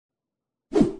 Pop Bubble
Pop Bubble is a free ui/ux sound effect available for download in MP3 format.
509_pop_bubble.mp3